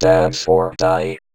VVE1 Vocoder Phrases 11.wav